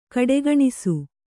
♪ kaḍegaṇisu